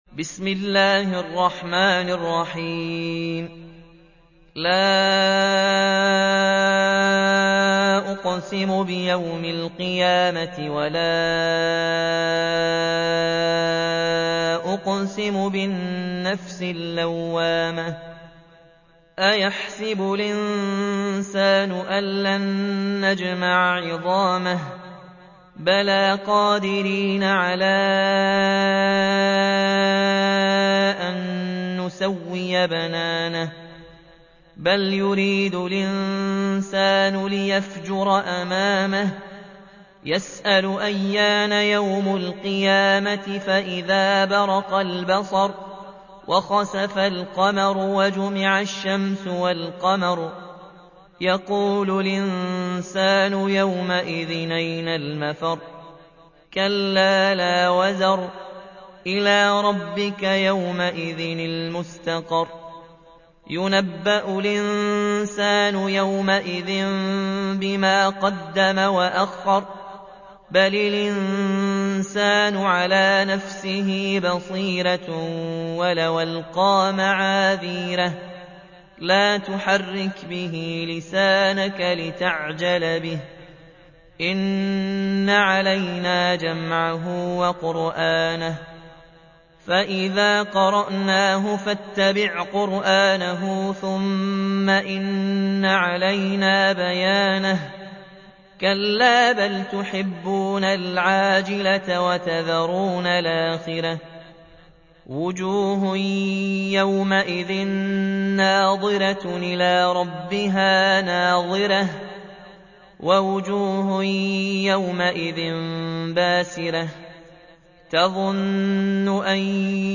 تحميل : 75. سورة القيامة / القارئ ياسين الجزائري / القرآن الكريم / موقع يا حسين